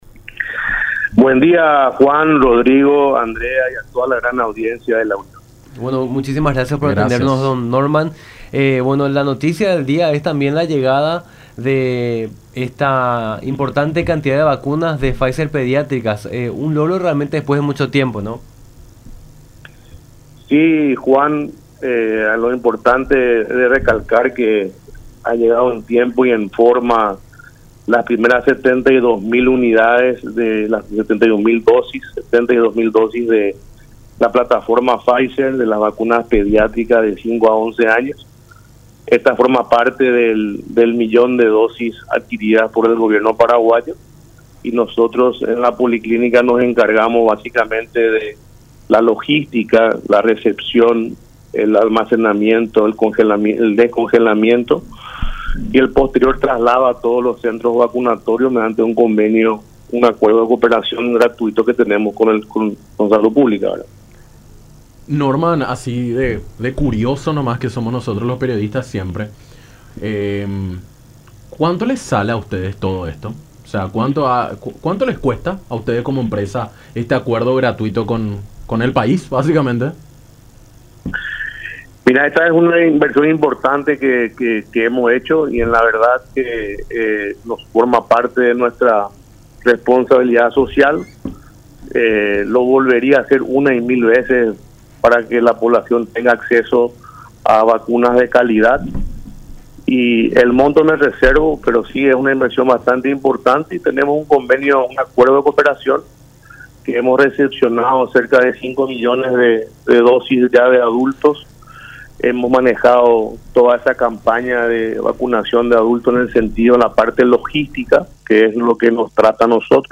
en conversación con Nuestra Mañana por La Unión.